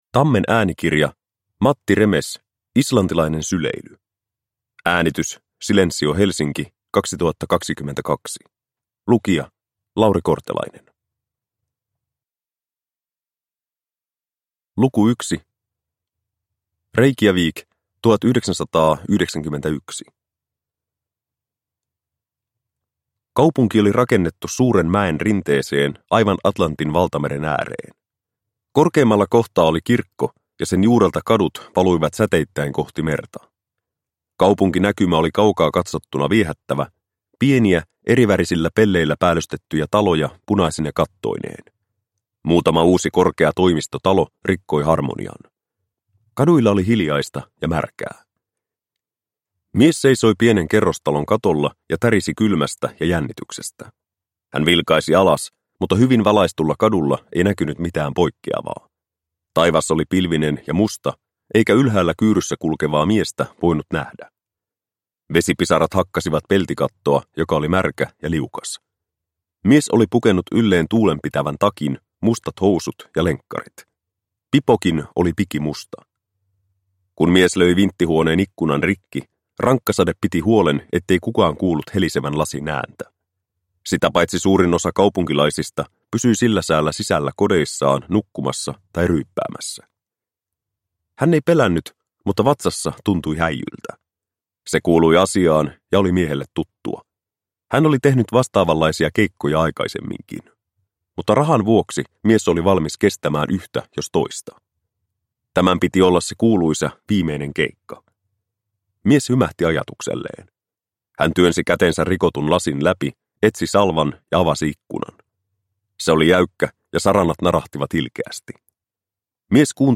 Islantilainen syleily – Ljudbok – Laddas ner